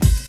Wu-RZA-Kick 26.WAV